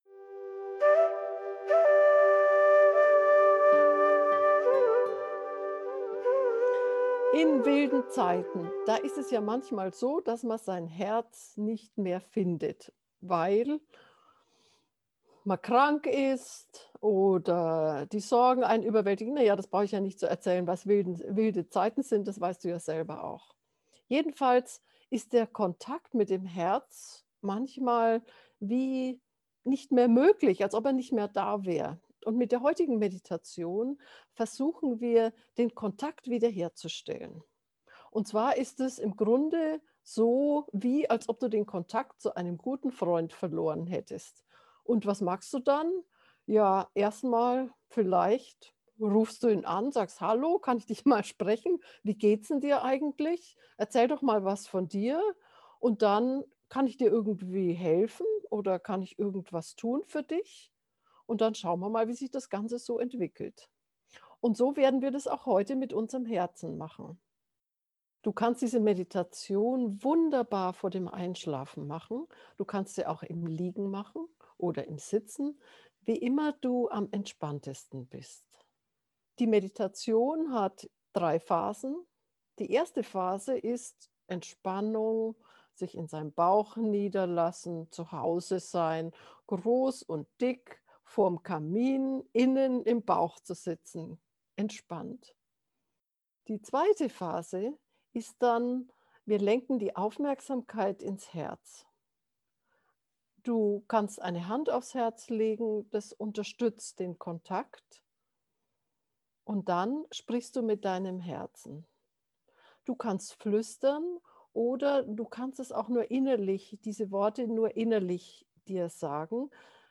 Mit dieser geführten Herzmeditation besuchen wir unseren Freund, das Herz, und fragen ihn, wie es ihm geht.
kontakt-herz-bekommen-gefuehrte-meditation